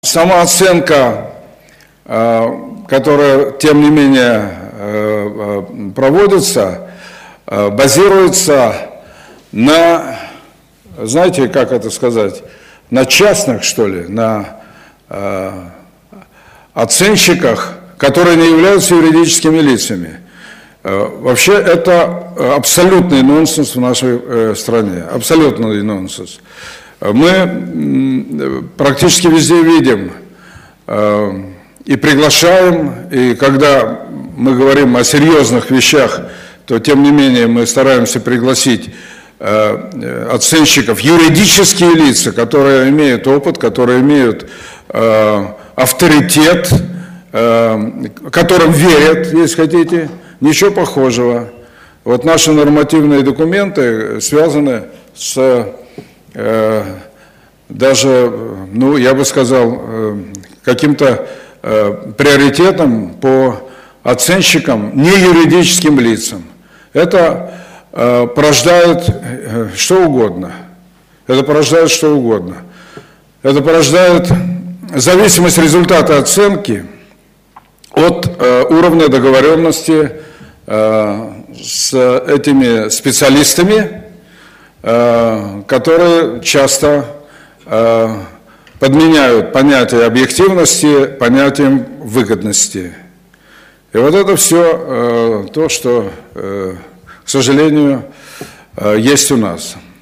Аудиозапись высказывания Лужкова Ю.М., по вопросу исключения юридических лиц из состава субъектов оценочной деятельности, на  Международной конференции «Оценка и налогообложение недвижимости: международный опыт и российский выбор»